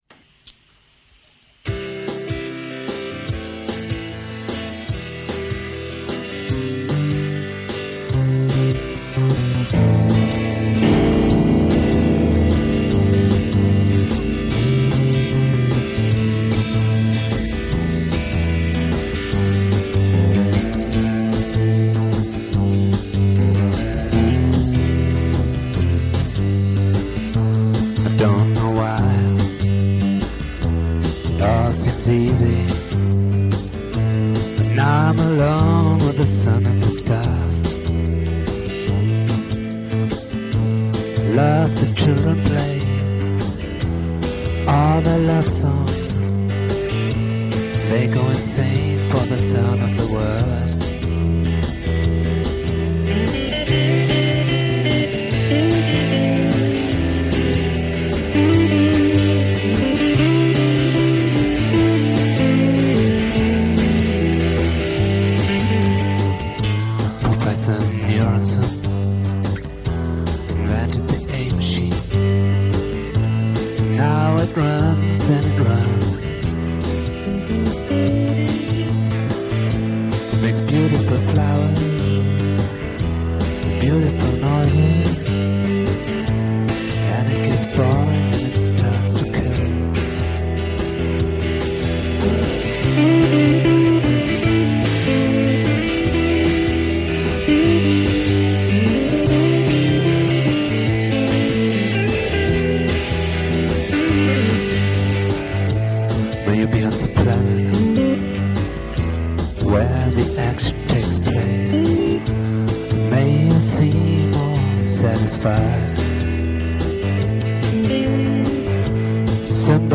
draft/rehearsal recording (see info), real audio file format
2) bigger file, better sound, stereo, 40kb/sec :
Limited means did not allow a better recording yet.